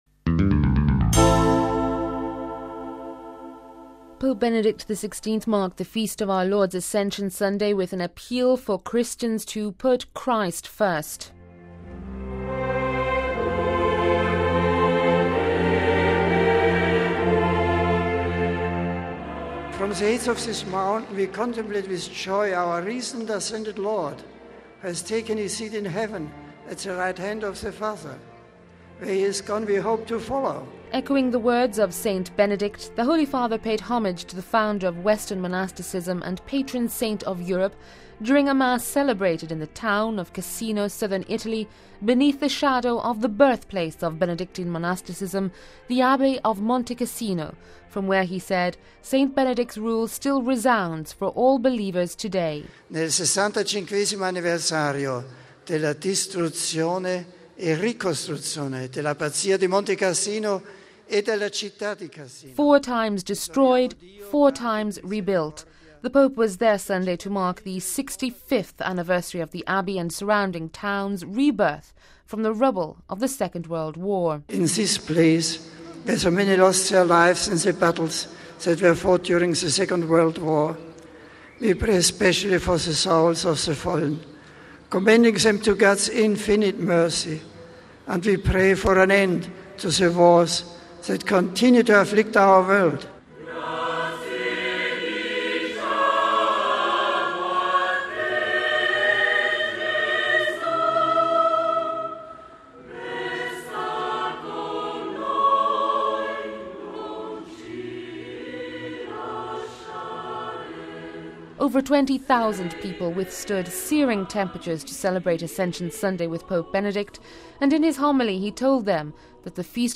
Echoing the words of St Benedict, the Holy Father paid homage to the founder of western monasticism and patron saint of Europe during mass celebrated in town of Cassino southern Italy, beneath the shadow of the birthplace of Benedictine monasticism, the Abbey of Montecassino, from where he said St Benedict’s Rule still resounds for all believers today.
Over 20 thousand people withstood searing temperatures to celebrate Ascension Sunday with Pope Benedict.